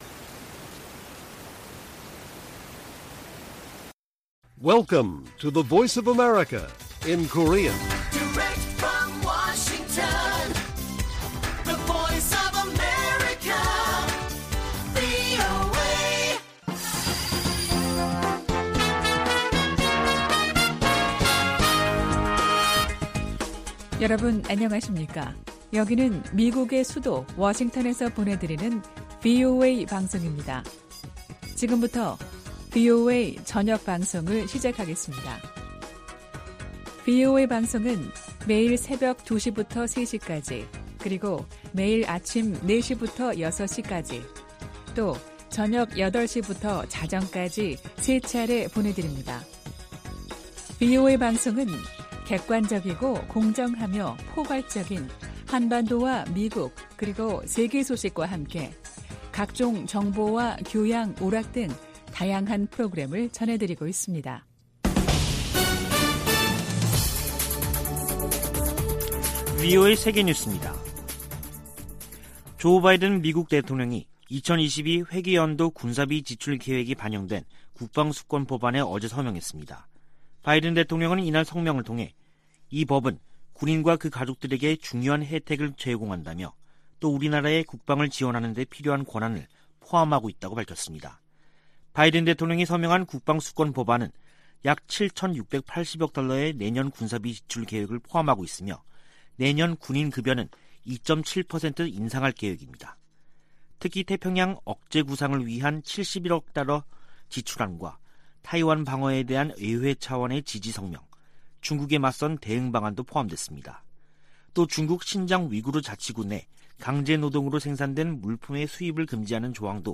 VOA 한국어 간판 뉴스 프로그램 '뉴스 투데이', 2021년 12월 28일 1부 방송입니다. 북한이 27일 김정은 국무위원장 주재로 올 들어 네번째 노동당 전원회의를 개최했습니다. 조 바이든 미국 행정부는 출범 첫 해 외교를 강조하며 북한에 여러 차례 손을 내밀었지만 성과를 거두진 못했습니다.